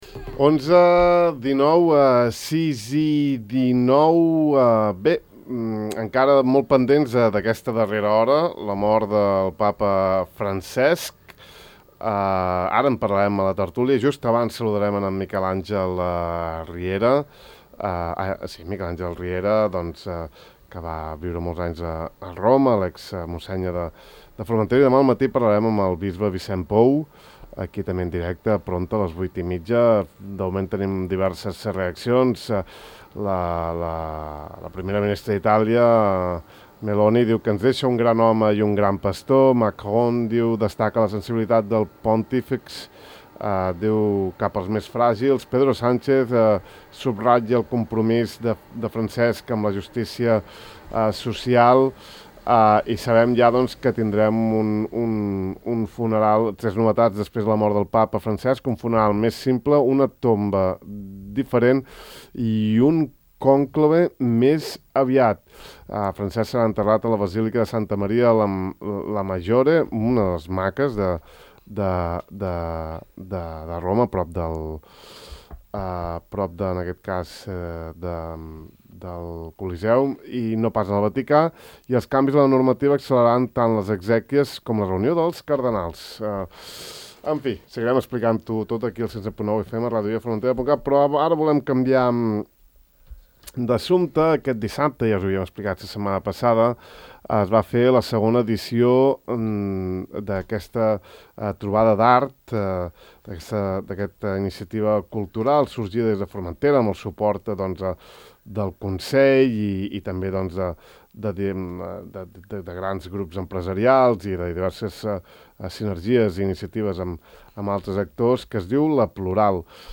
Avui ens ha visitat al De far a far. Podeu escoltar l’entrevista sencera aquí: